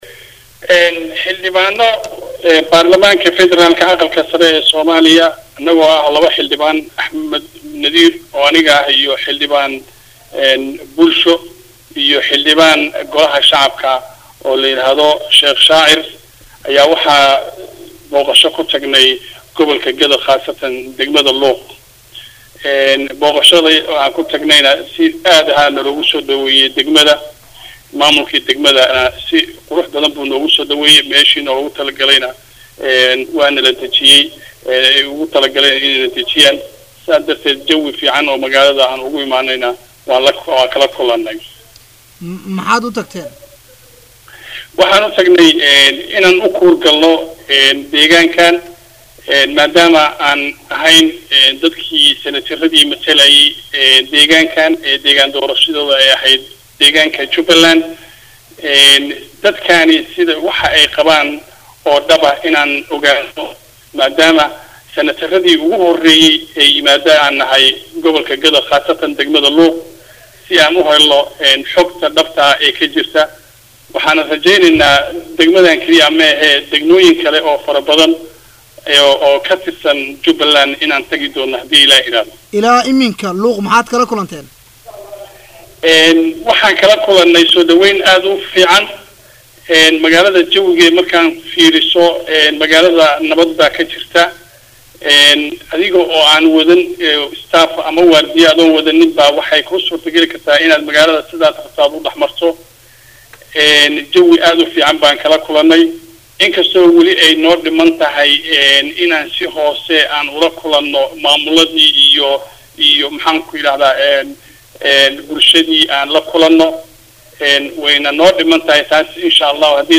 Waraysi-Senatar-Axmed-Macalin-Cumar-Nadiir.mp3